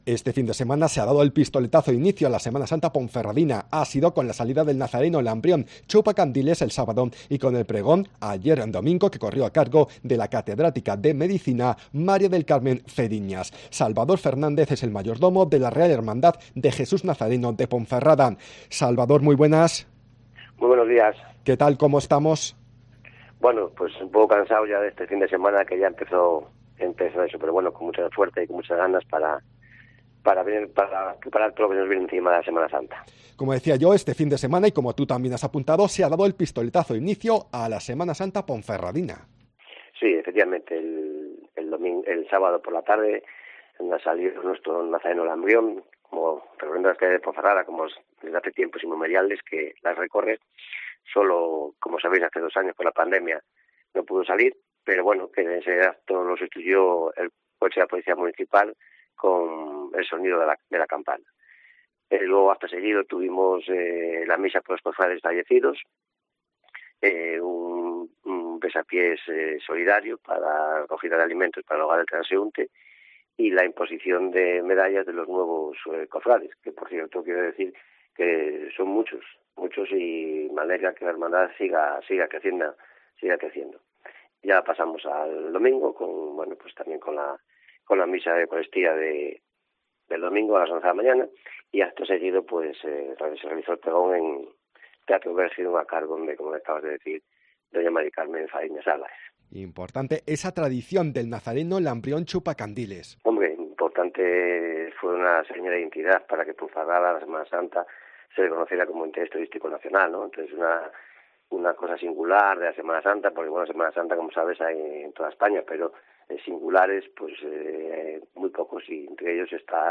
SOCIEDAD-MEDIODÍA COPE